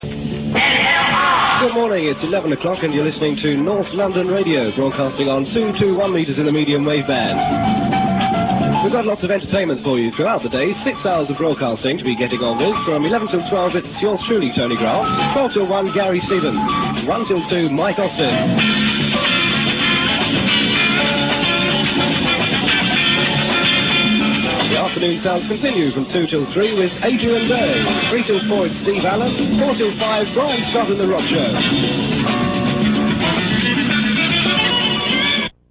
Here you can listen to off air and studio recordings of landbased pirate radio stations, they feature stations based in London and the home counties from the late 70's to almost the present day